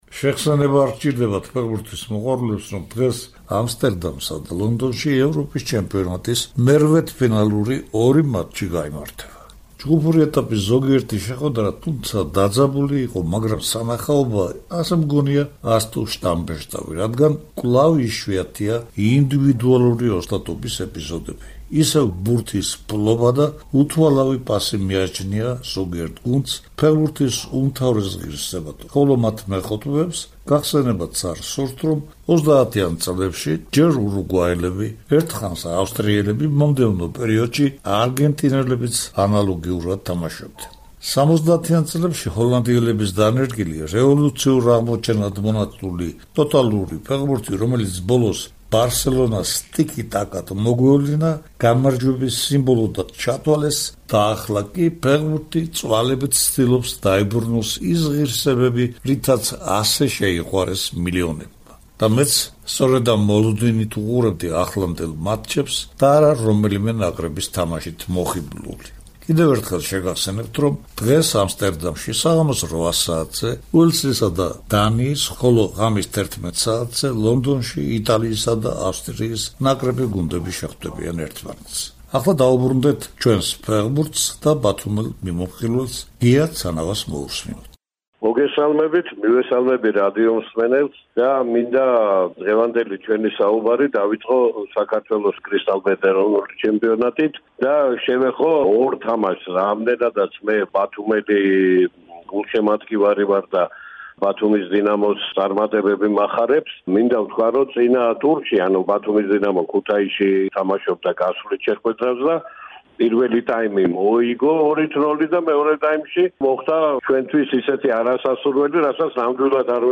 ევროპის პირველობის ჯგუფური ეტაპისა და საქართველოს ეროვნული საფეხბურთო ჩემპიონატის მიმოხილვა; დავით ყიფიანის გახსენება - 1981 წლის 8 მაისსა და 1996 წლის 13 მაისს (გამარჯვების საიუბილეო დღეს) ჩაწერილი ინტერვიუების ფრაგმენტები; ახალგაზრდა ქართველი წყალბურთელების წარმატებებსა და მომავალ დიდ...